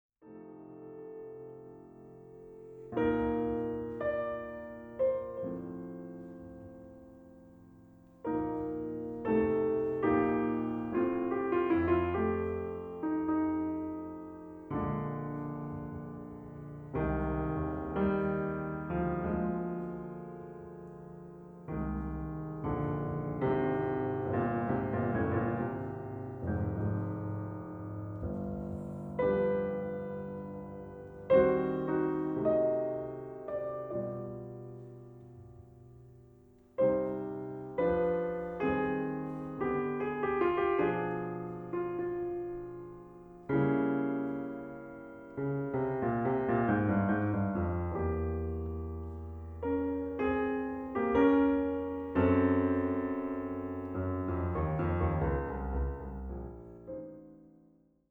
Genre : Classique